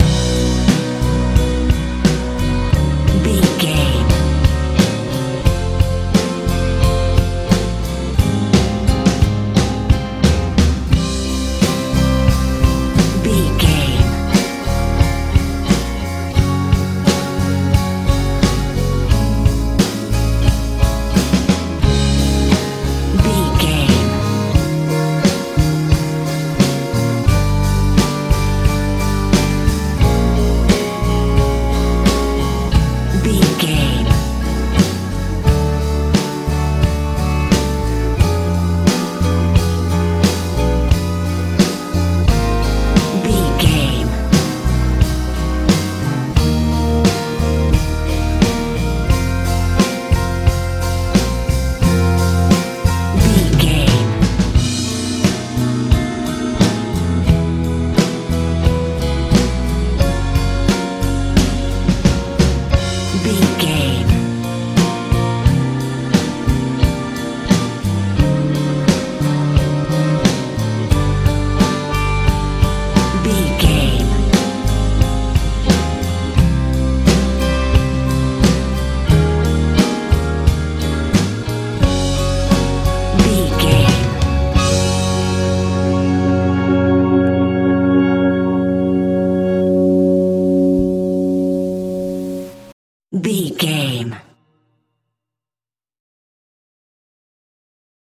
lite pop feel
Ionian/Major
light
dreamy
organ
acoustic guitar
bass guitar
drums
soft
relaxed